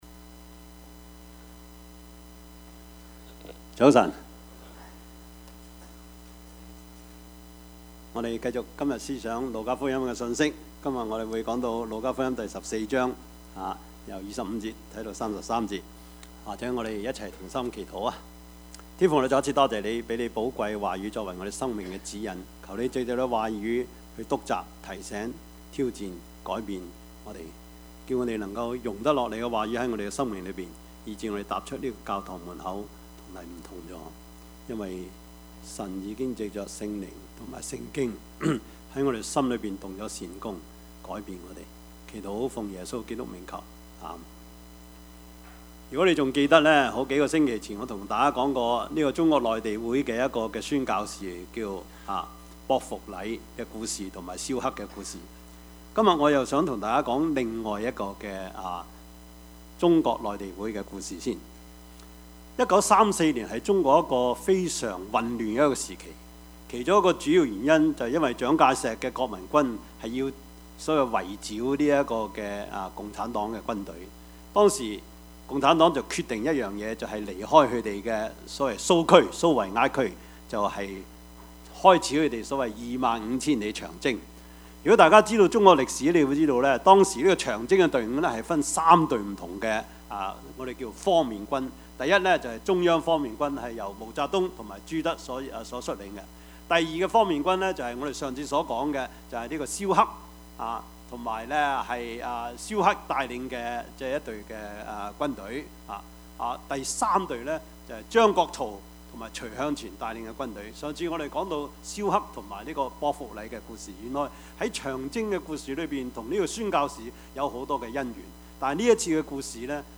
Service Type: 主日崇拜
Topics: 主日證道 « 一個父親的叮嚀 伊甸園與新天新地 »